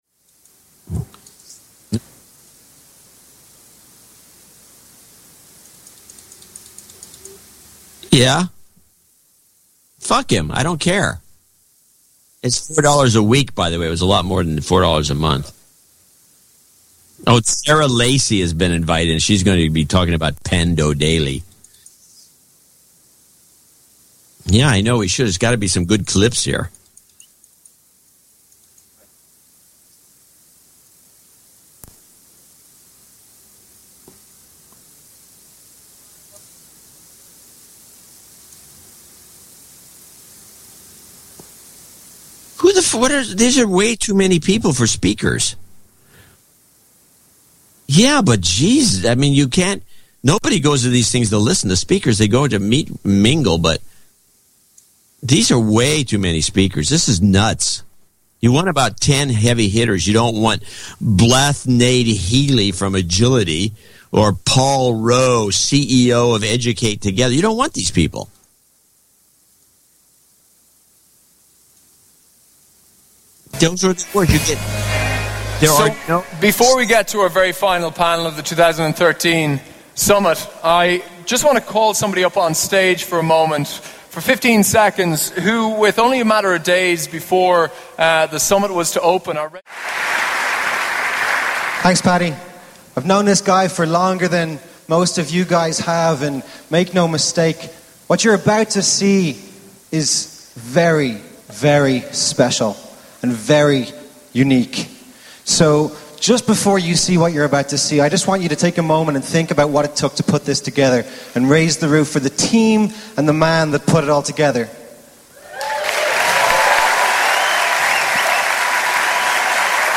The secret NSA recording of JCD's after show chat on 10/31/2013.
Only his voice is heard, we can only speculate what his redacted cohort was saying.